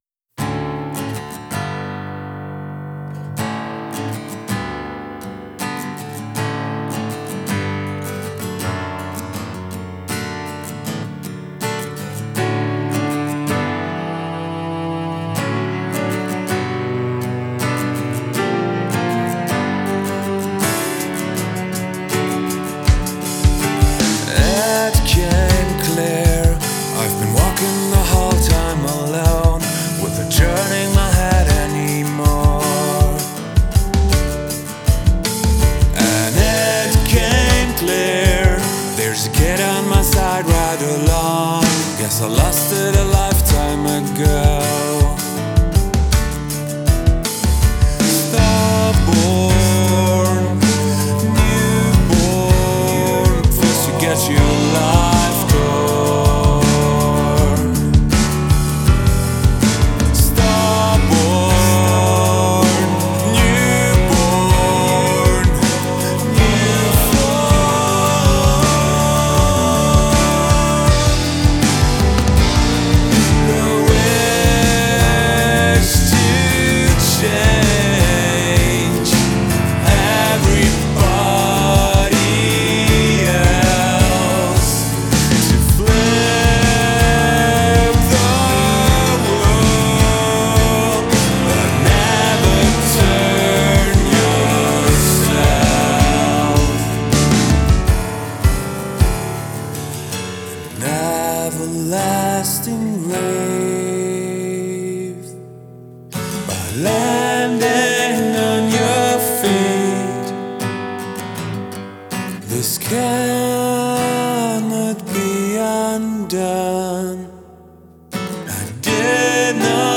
a solemn track processing massive changes in private life.
So the song has some melancholic but positive vibe
a voice telling an emotional story